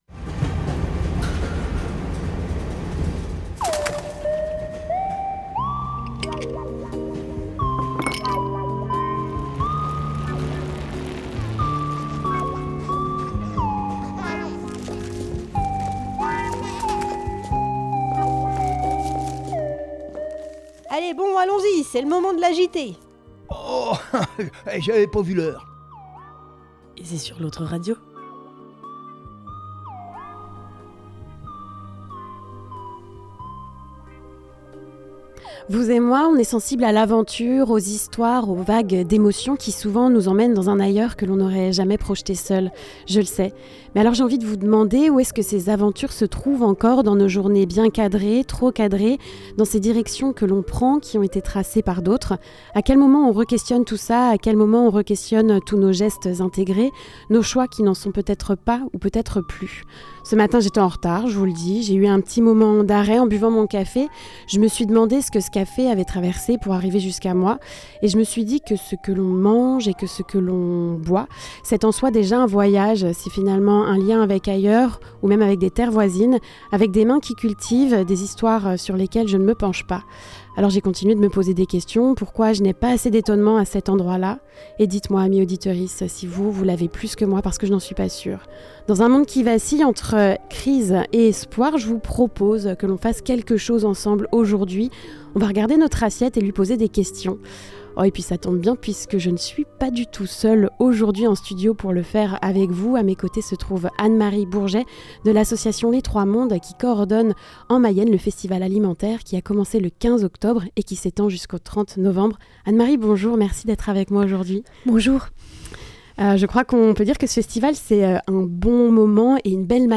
Le Micro Trottoir de la semaine